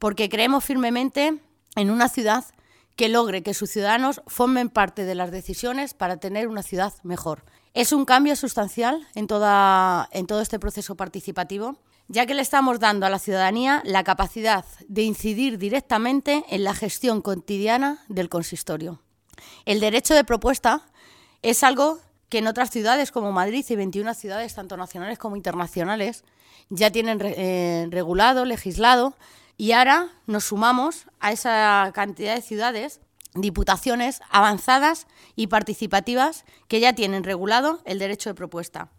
AUDIOS. Helena Galán, concejala de Participación y Transparencia